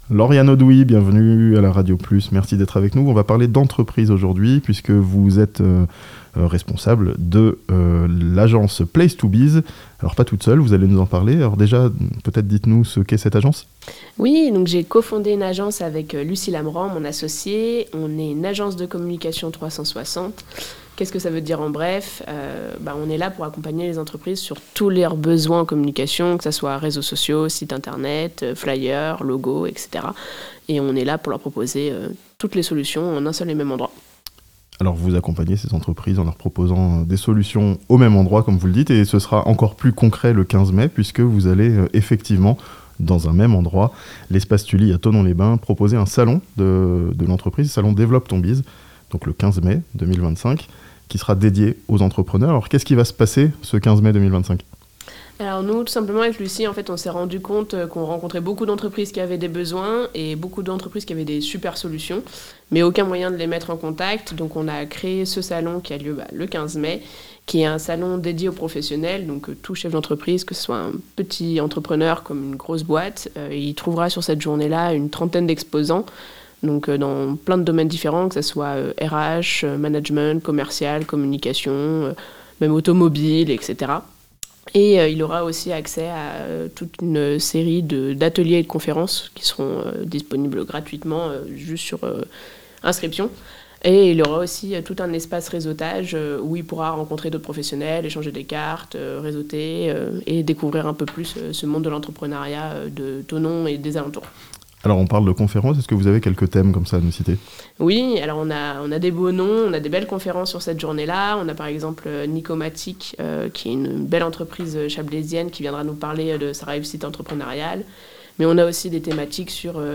Un salon dédié aux entrepreneurs, à Thonon le 15 mai (interview)